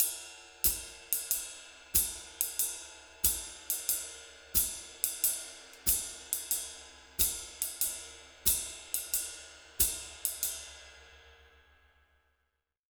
92SWING 01-R.wav